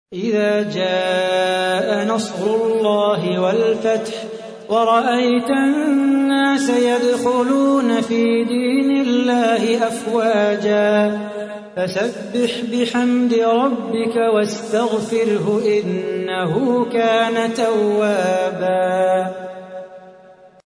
تحميل : 110. سورة النصر / القارئ صلاح بو خاطر / القرآن الكريم / موقع يا حسين